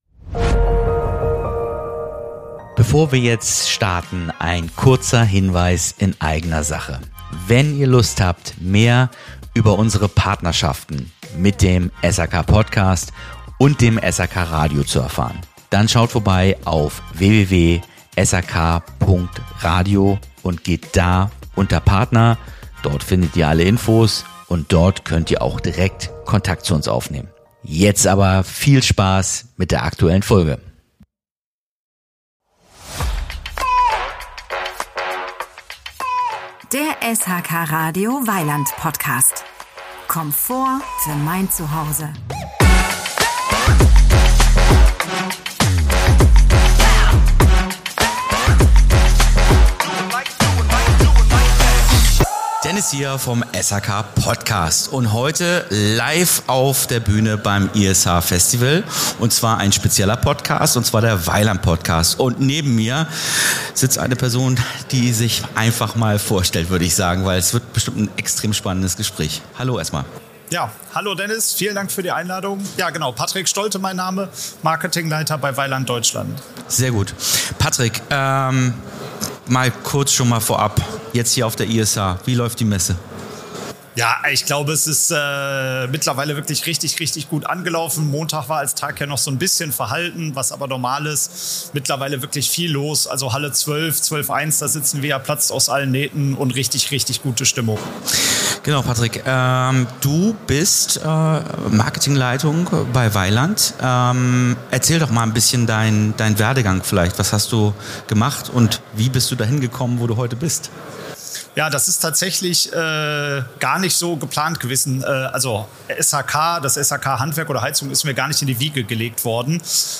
Ein praxisnahes Gespräch über Kommunikation, Produktstrategie und Veränderung in einer Branche im Wandel – direkt von der Messebühne.